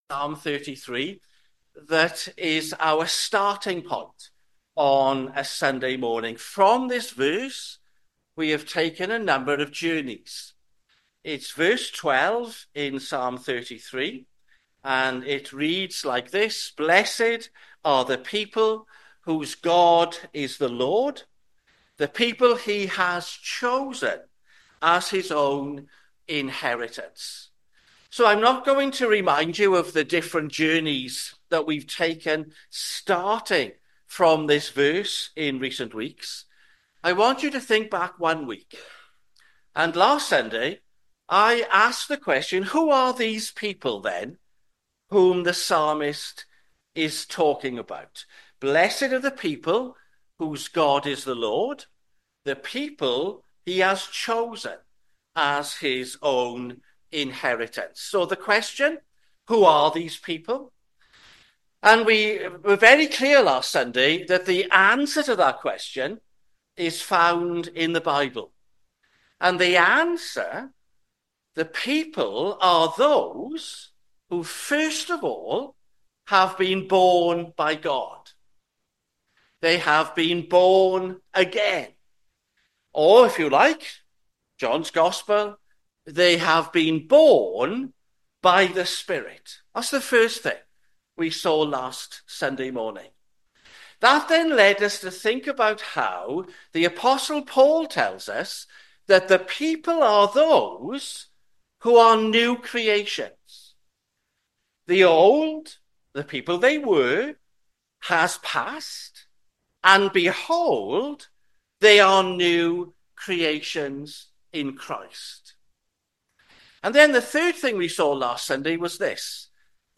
sermon-2025-c-9th-march-am.mp3